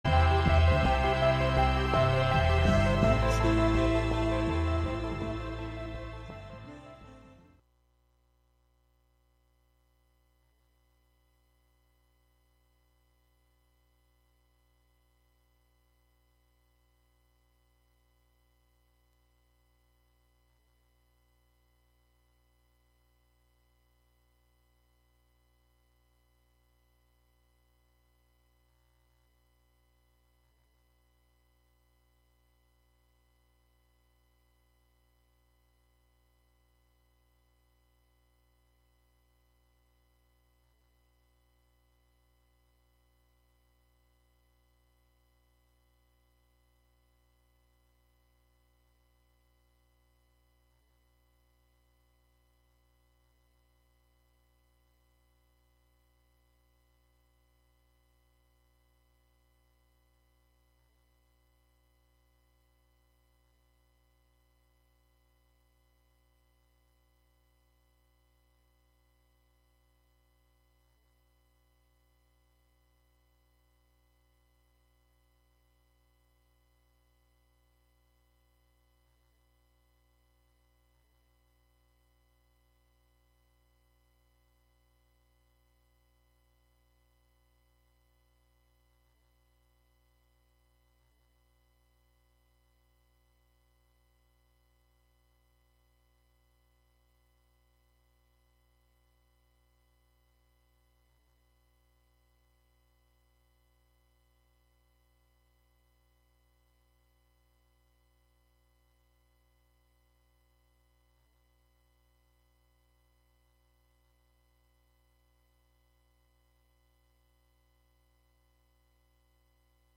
Music, talk, and schtick, just like any variety show. One difference, though, is the Democratic Socialists also confront power.